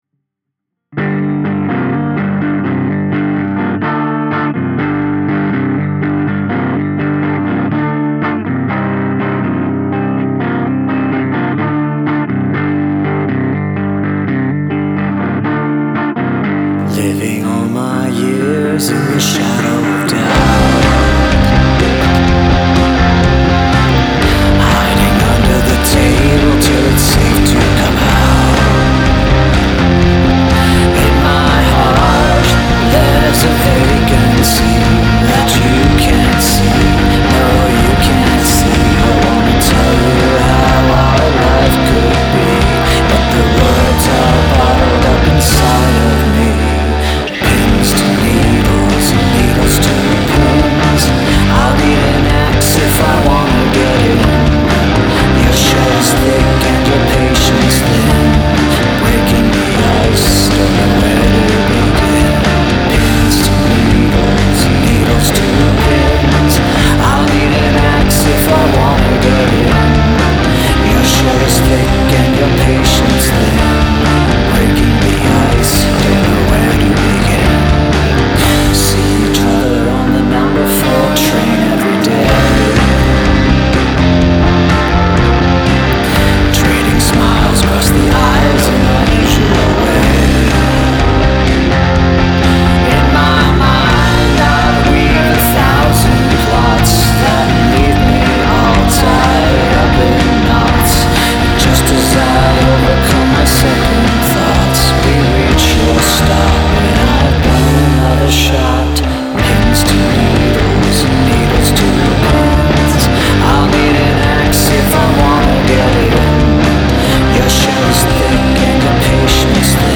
Significant Use of Shouting